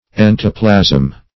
Search Result for " entoplasm" : The Collaborative International Dictionary of English v.0.48: Entoplasm \En"to*plasm\, n. [Ento- + Gr.